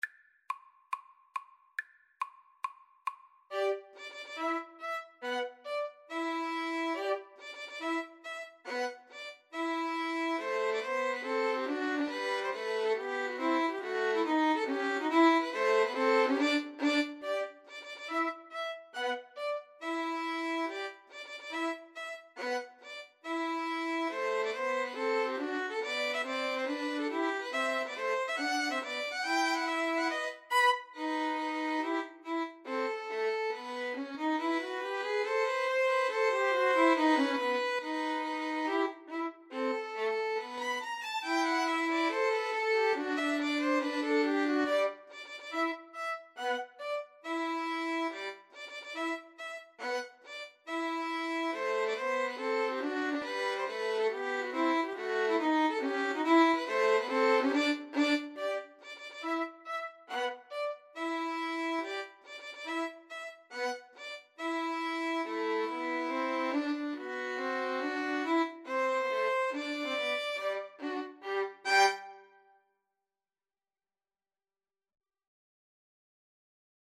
G major (Sounding Pitch) (View more G major Music for Violin Trio )
Tempo di marcia =140
Classical (View more Classical Violin Trio Music)